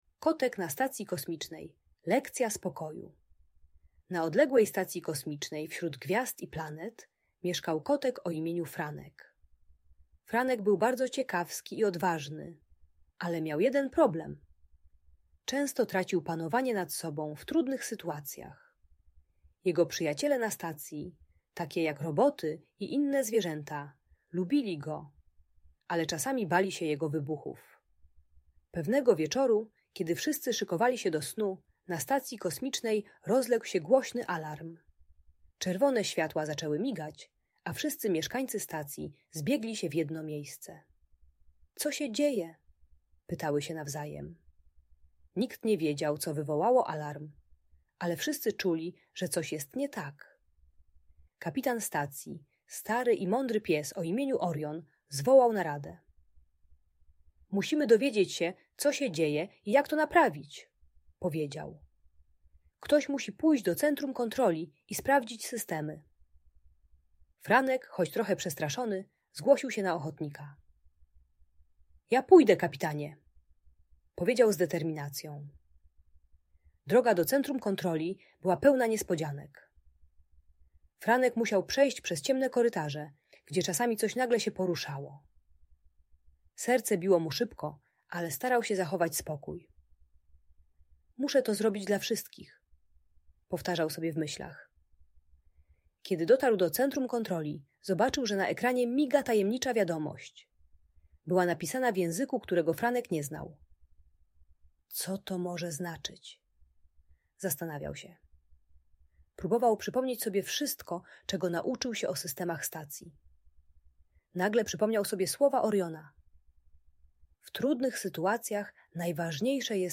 Historia Franka na Kosmicznej Stacji - Bunt i wybuchy złości | Audiobajka